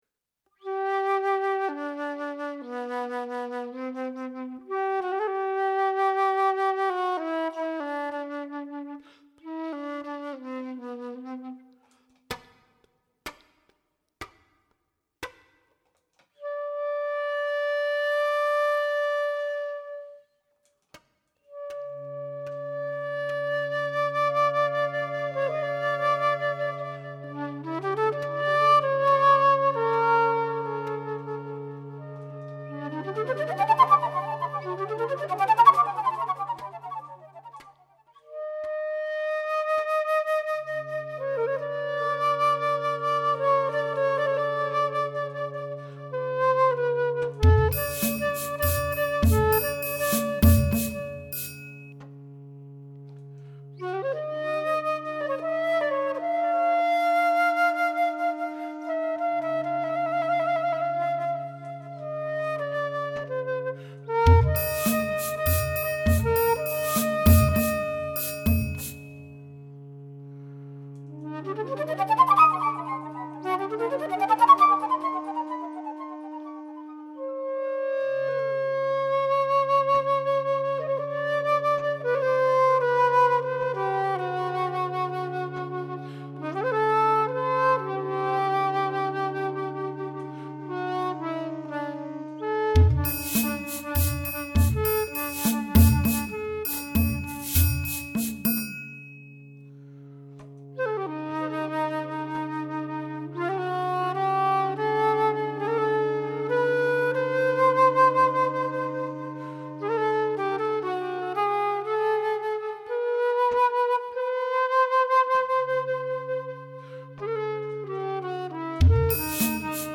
flute and electronics